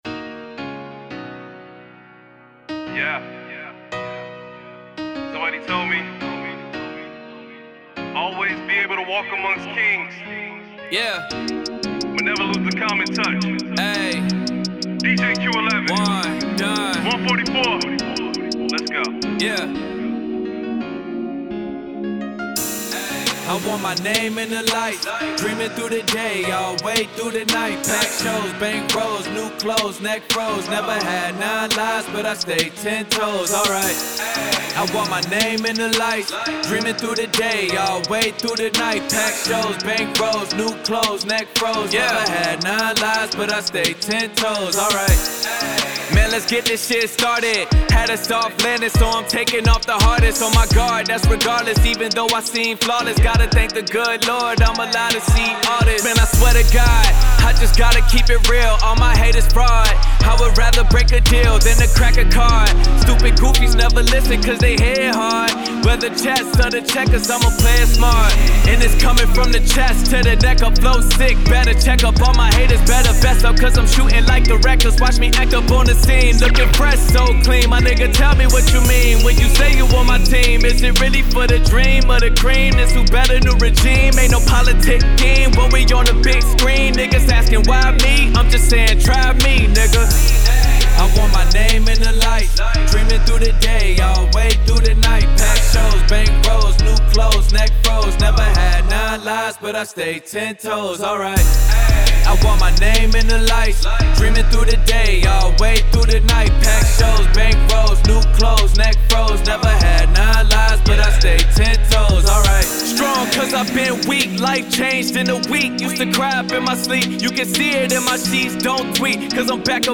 Hiphop
Hip Hop song